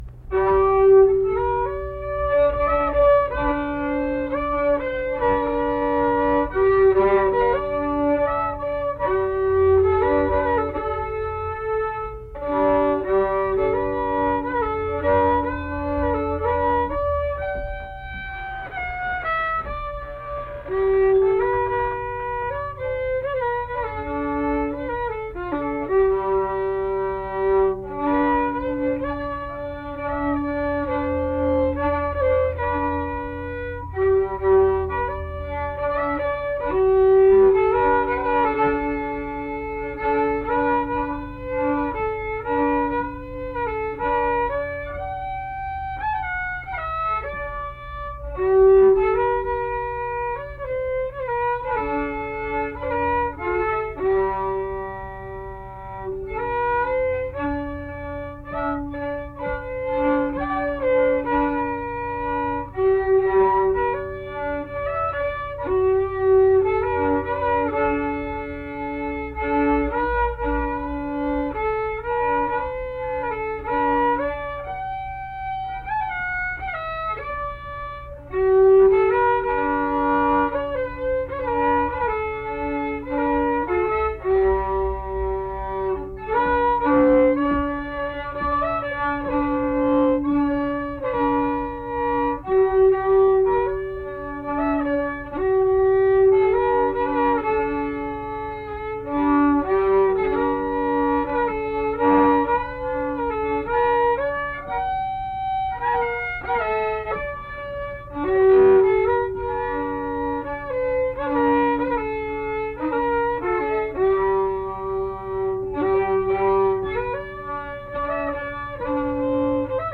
Accompanied guitar and unaccompanied fiddle music performance
Instrumental Music
Fiddle
Mill Point (W. Va.), Pocahontas County (W. Va.)